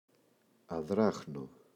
αδράχνω [a’ðraxno]